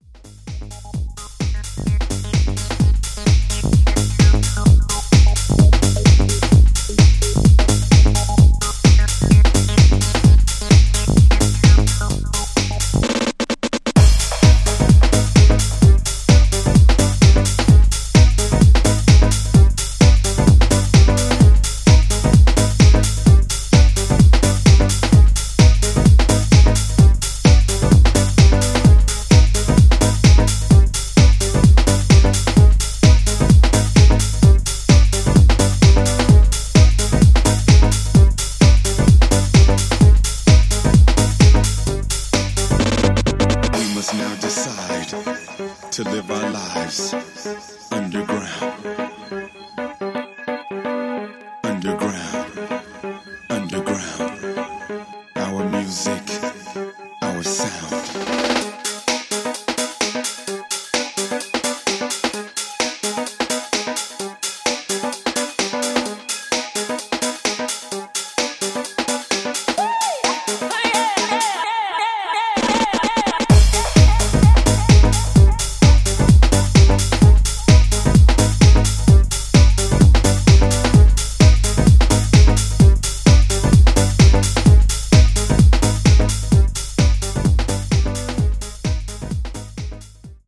ここでは、モダンでバウンシーなプログレッシヴ・ハウス路線の4曲を展開。
古典的ディープ・ハウスに通じるリフで淡々とフロアを沸かせる